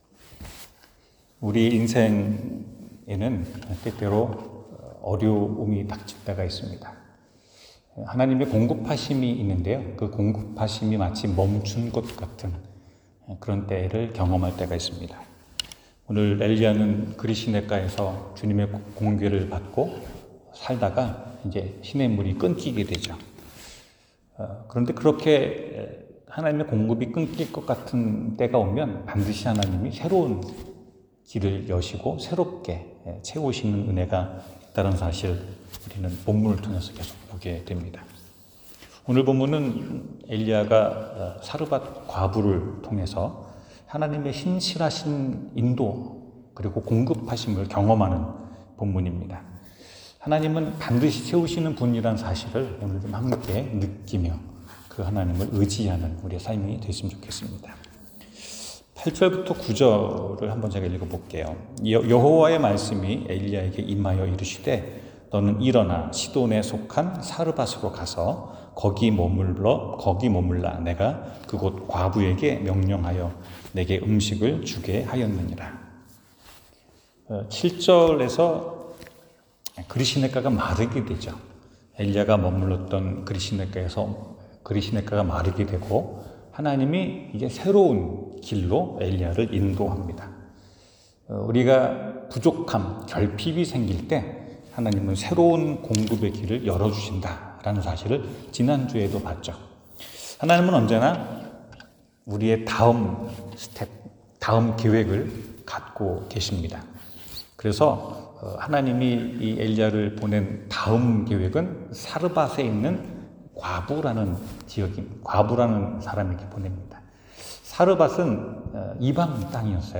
주께서 채우시리라 성경 : 열왕기상 17:8-16 설교